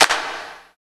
108 W.CLAP-L.wav